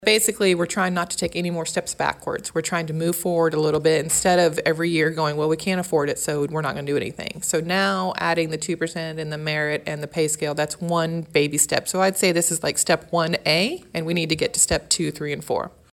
Commissioner Becky Smith says Wednesday’s discussions are not a permanent fix for the wage discrepancy, however, she thinks it is a good starting point.